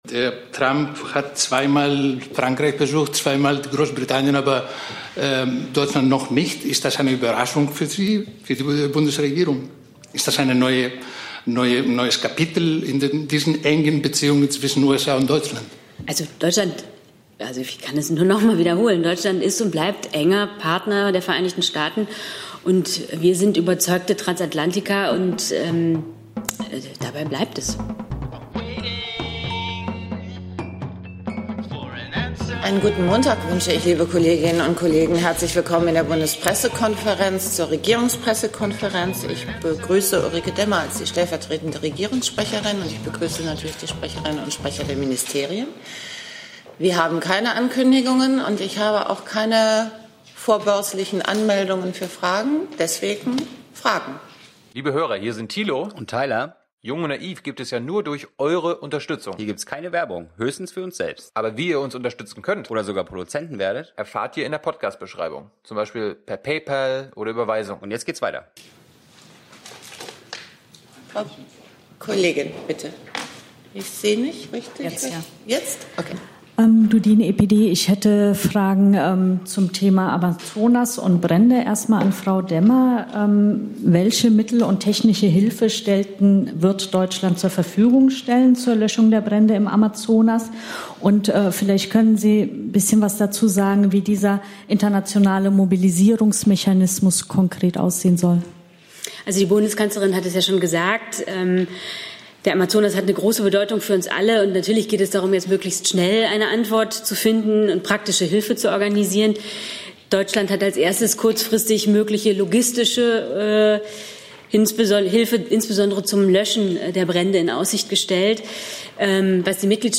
Regierungspressekonferenz in der Bundespressekonferenz Berlin, 26.08.2019 Themen: 01:00 Amazonas 08:33 Vermögenssteuer 09:20 Klimaziele 10:45 Energiebilanz der Ministerien 13:35 LKW-Maut-Einnahmen (BMU vs. BMVI) 16:57 Klimaanleihen 18:56 Wahlkämpfender Fi...